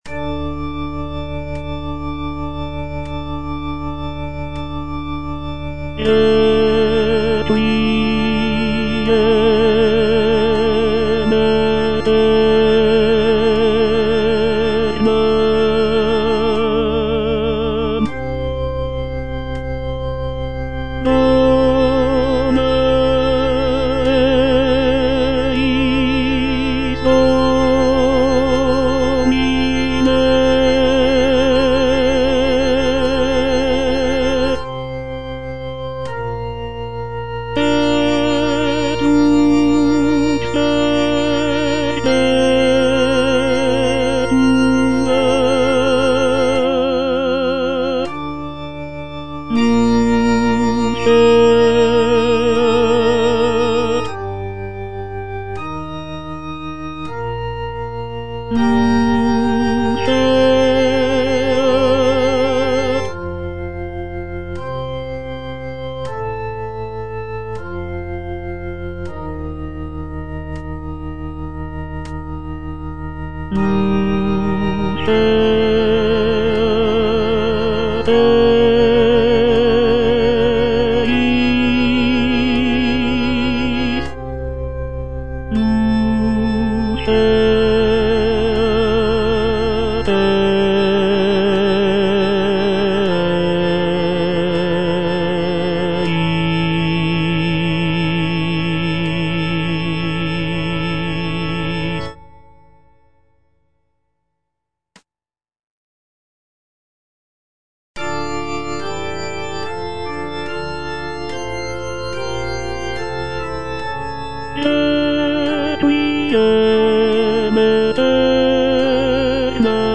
version with a smaller orchestra
(tenor II) (Voice with metronome) Ads stop